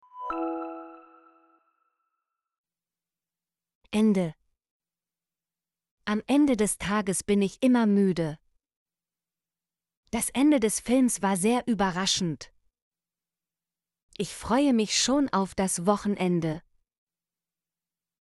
ende - Example Sentences & Pronunciation, German Frequency List